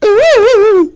Play, download and share Husky espasmos original sound button!!!!
husky_espasmos.mp3